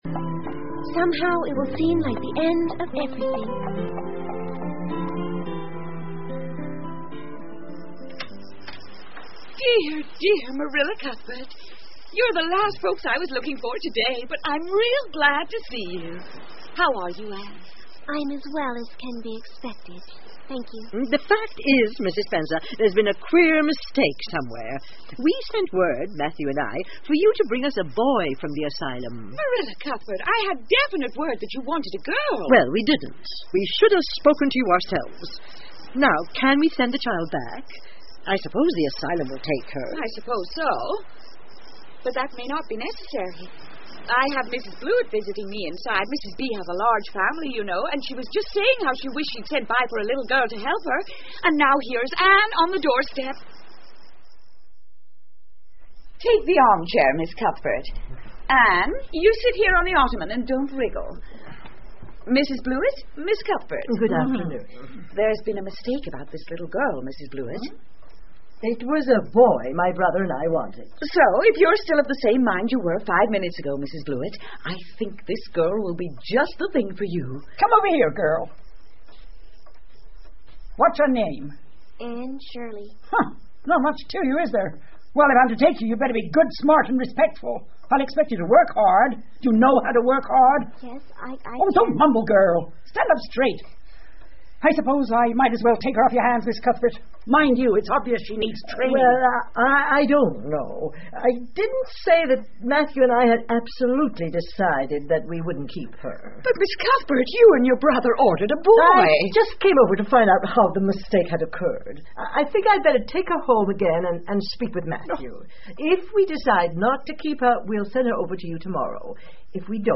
绿山墙的安妮 Anne of Green Gables 儿童广播剧 4 听力文件下载—在线英语听力室